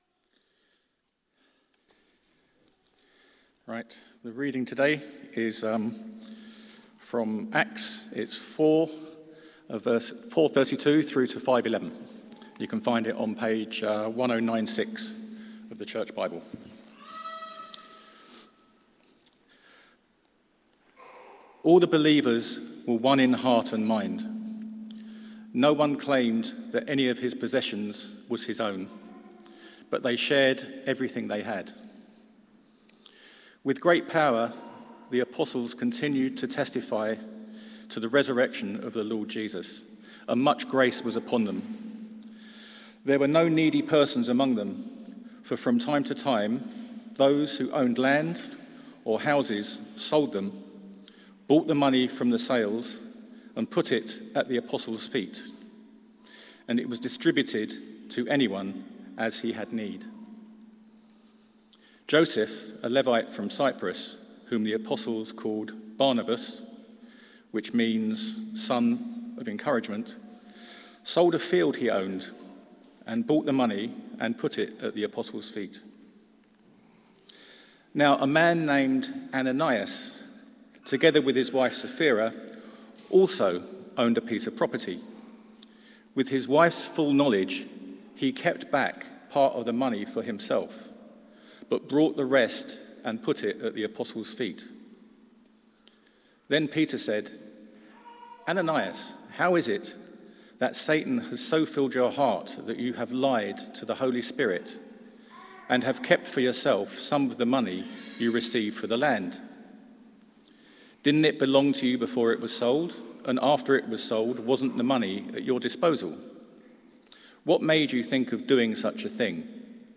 Telling Lies to God Sermon